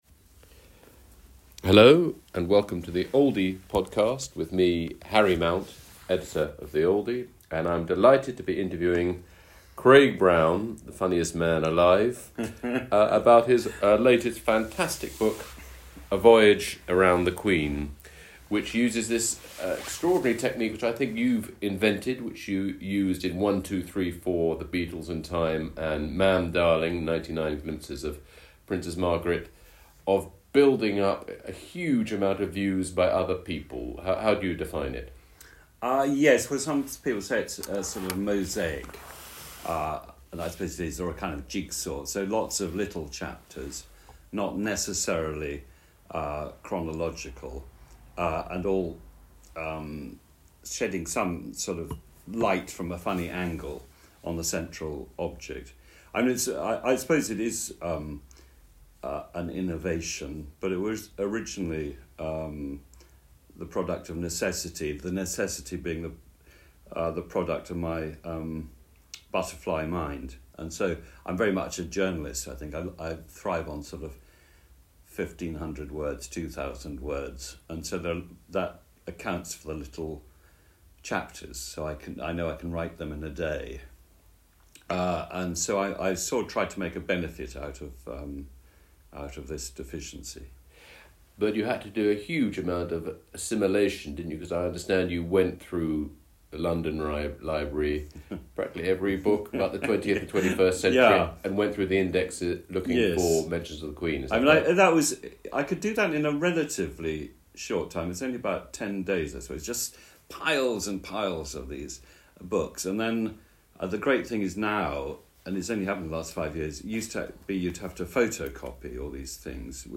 Oldie Podcast - Craig Brown in conversation with Harry Mount